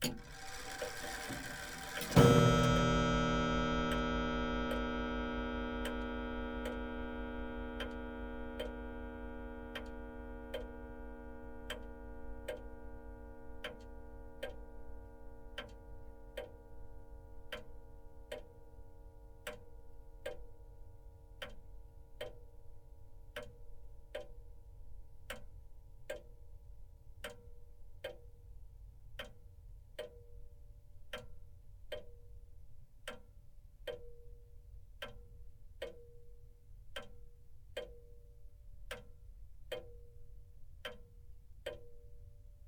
Grandfather_Clock_Sound_files